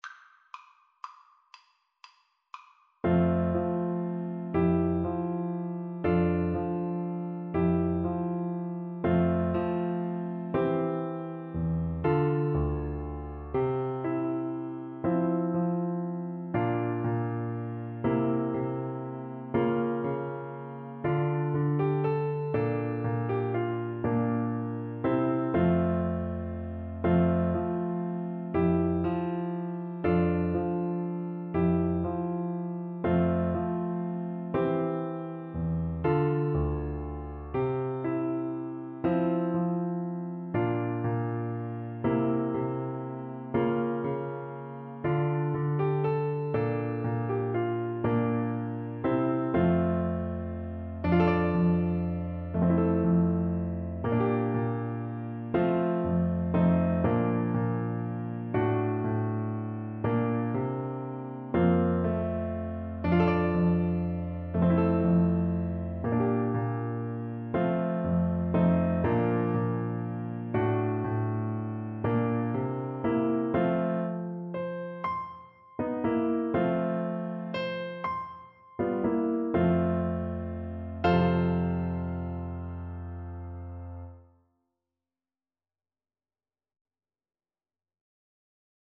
Play (or use space bar on your keyboard) Pause Music Playalong - Piano Accompaniment Playalong Band Accompaniment not yet available transpose reset tempo print settings full screen
El Noi de la Mare (The Child of the Mother) is a traditional Catalan Christmas song.
F major (Sounding Pitch) C major (French Horn in F) (View more F major Music for French Horn )
Lentissimo .= 40
6/8 (View more 6/8 Music)